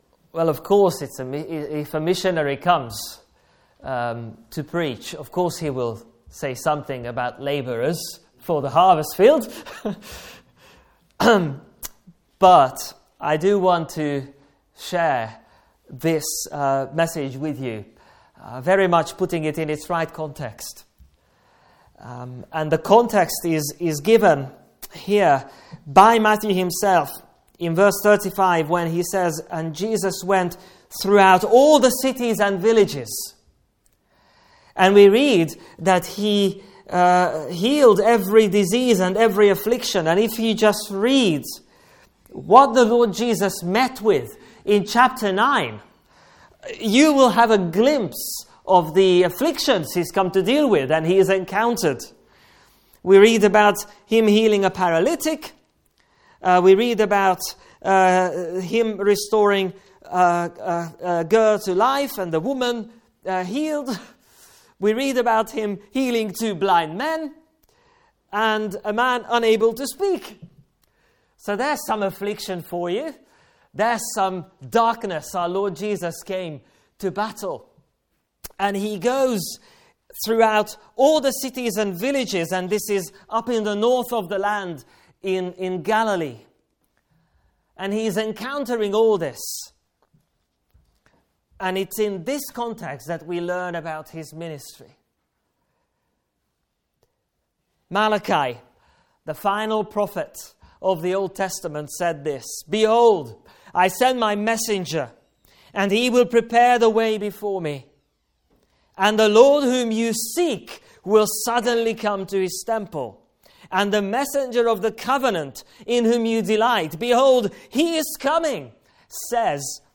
Guest Speaker
Passage: Ezekiel 34: 1-24; Matthew 9: 35-38 Service Type: Morning Service